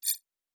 Digital Click 08.wav